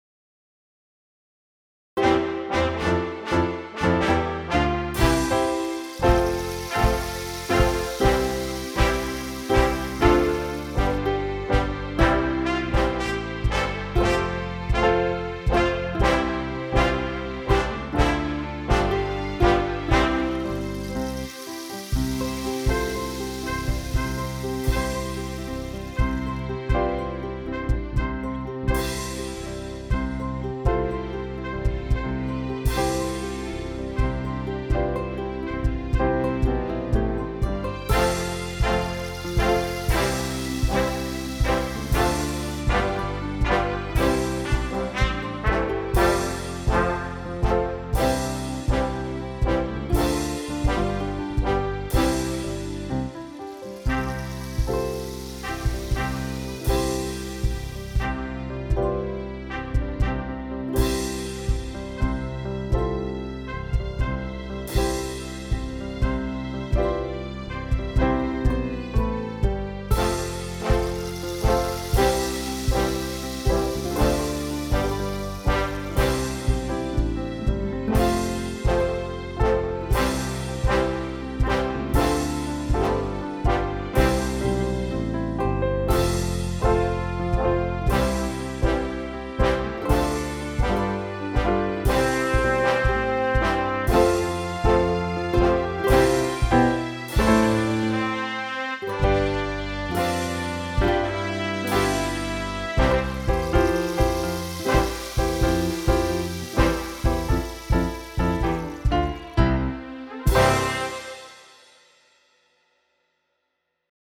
Every time I hear the Spirit   Descant line
Accompaniment (Copyright)